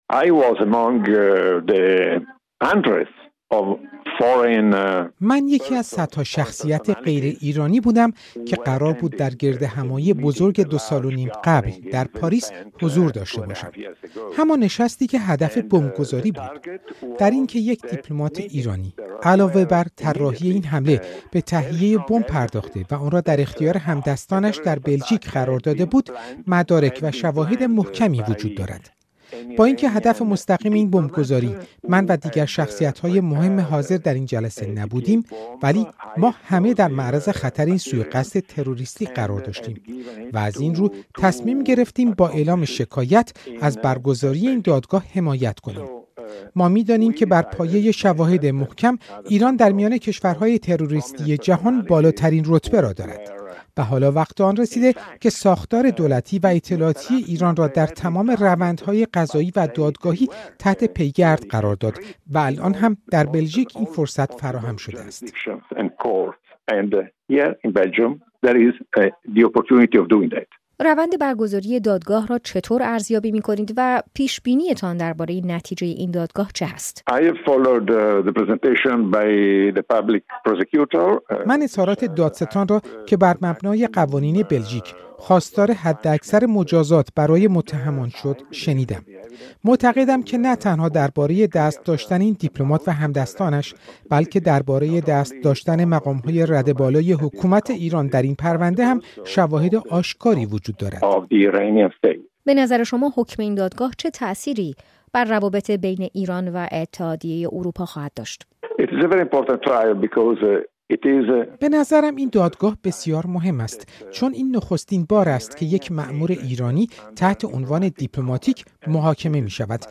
دادگاه دیپلمات ایرانی در بلژیک‌؛ گفت‌وگو با وزیر خارجه ایتالیا، از شاکیان پرونده